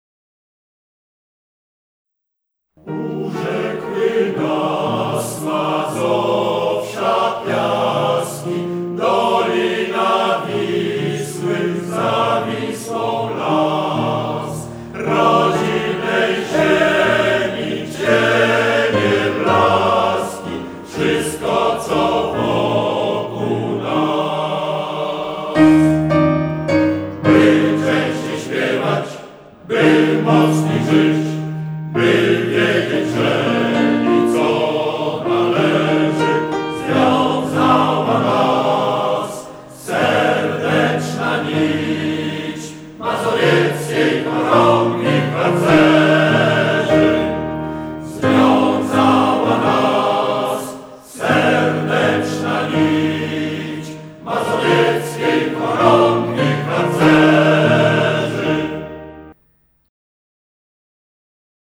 100-letni warszawski, męski chór